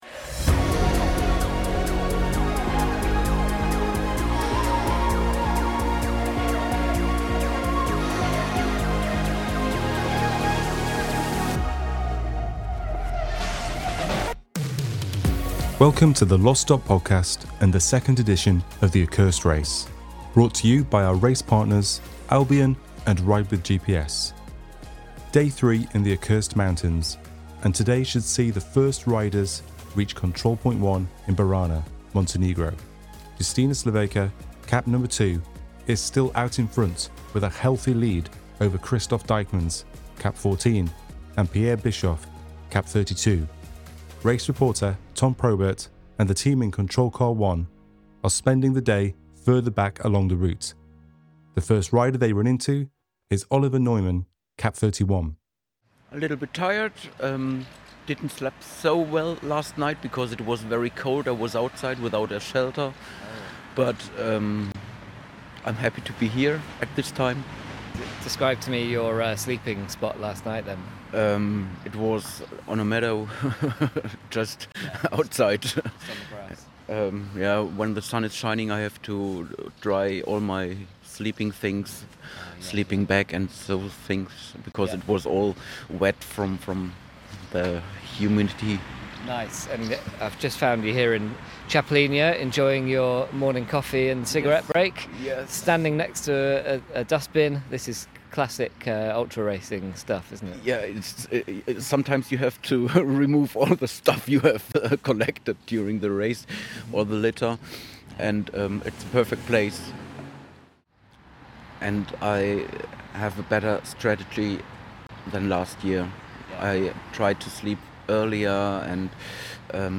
Tune in for covert rider and wildlife spotting with the media team, as they double down on their commitment to staying out of sight. At various resupply points, we are treated to storytime, with riders describing differing sleeping strategies and locations, the good, the bad, and the uncomfortable.